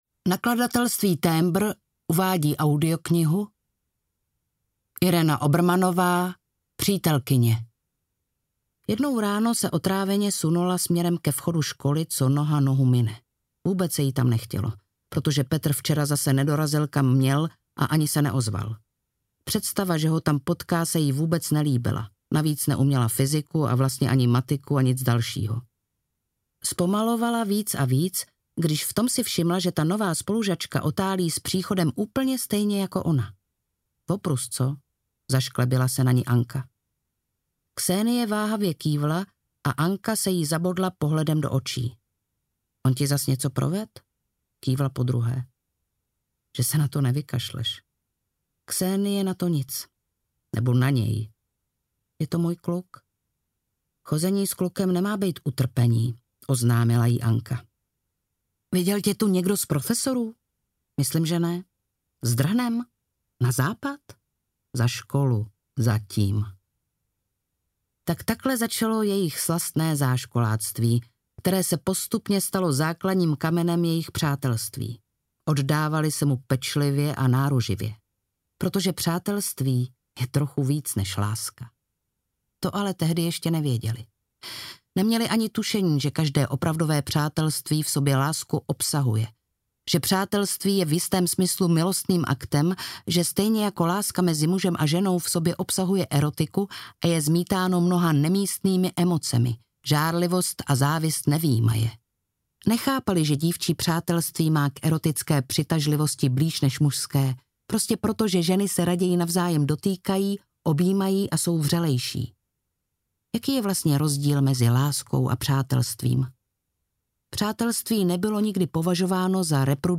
Přítelkyně audiokniha
Ukázka z knihy